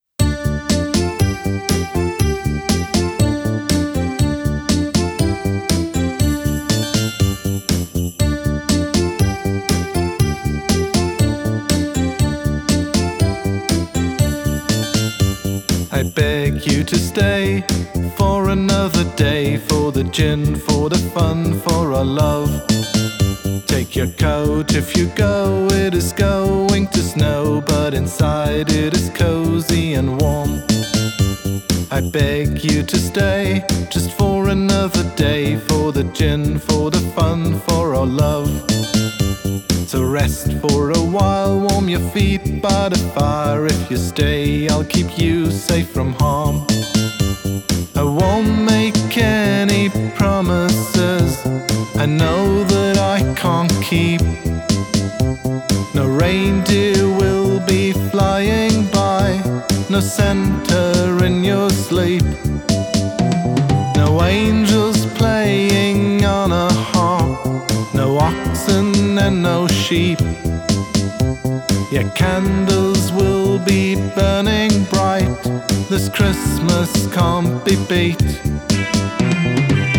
A Christmas song